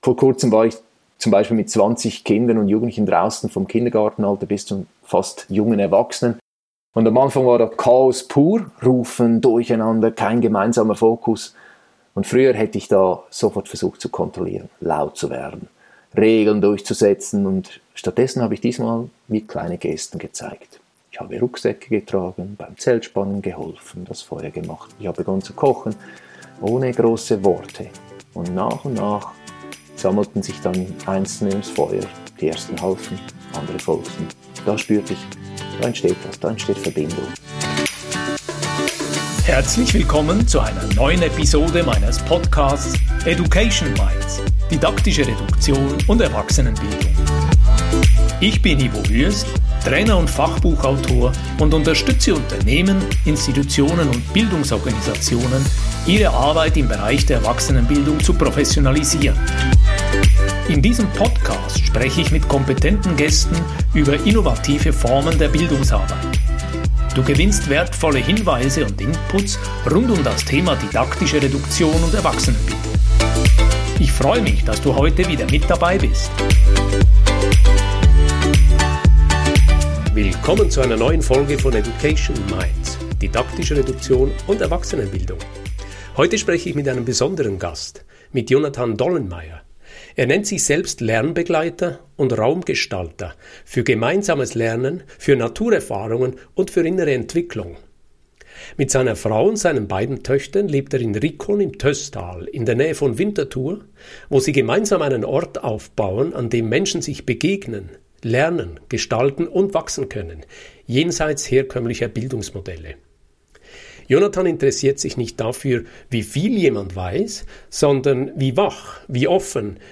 In diesem Gespräch geht es um die Sehnsucht nach echter Verbundenheit, das Gestalten von Räumen, in denen Lernen geschieht, und um die Frage, wie Erwachsene wieder ins Lernen kommen.